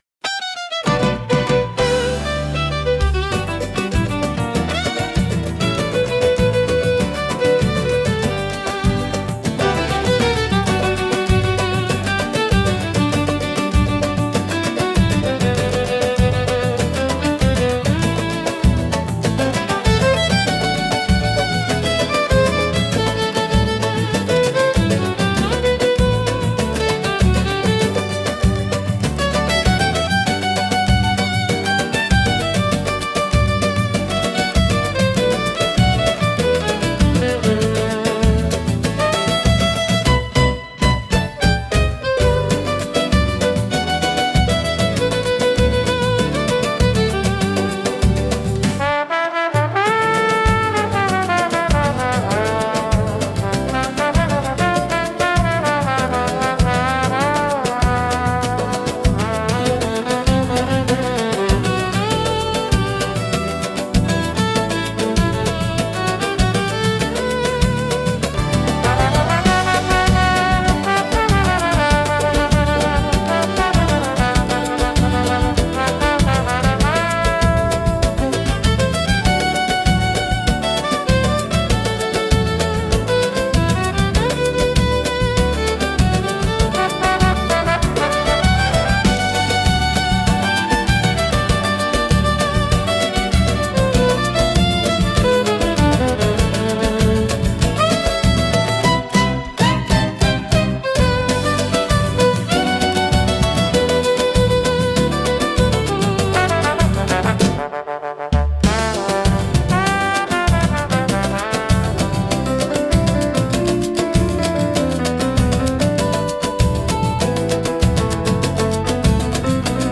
música, arranjo e voz: IA) (instrumental